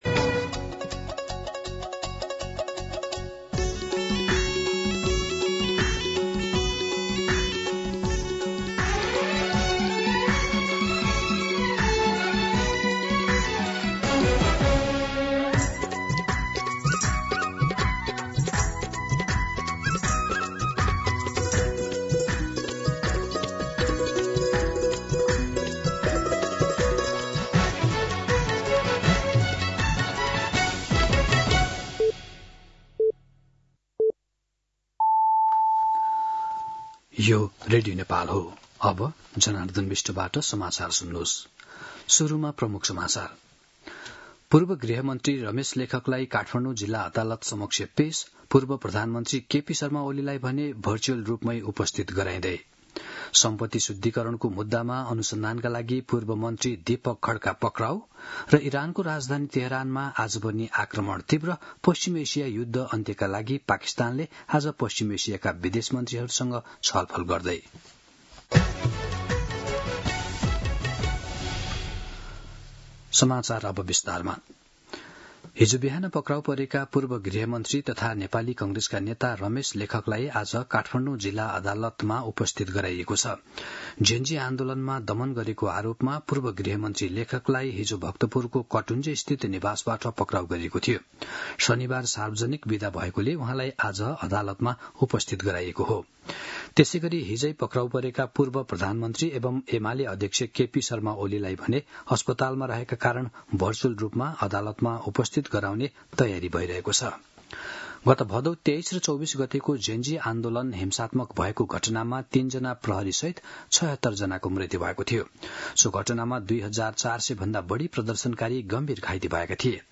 दिउँसो ३ बजेको नेपाली समाचार : १५ चैत , २०८२